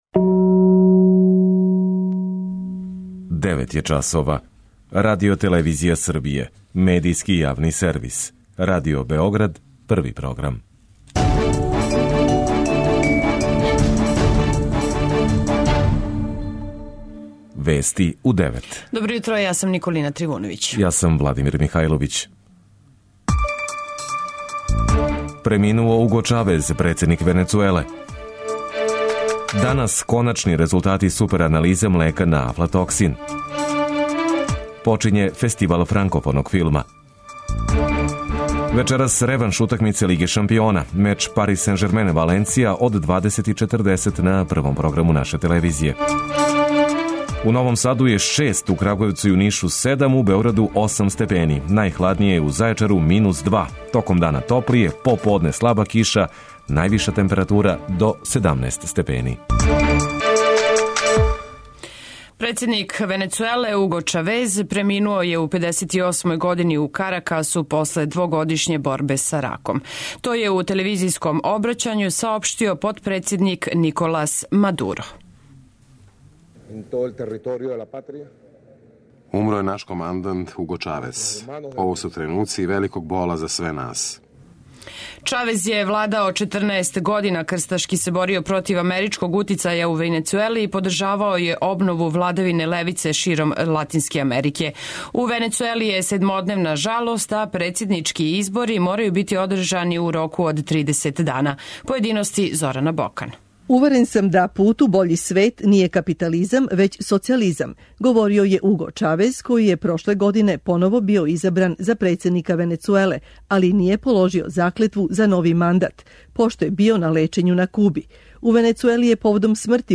преузми : 10.19 MB Вести у 9 Autor: разни аутори Преглед најважнијиx информација из земље из света.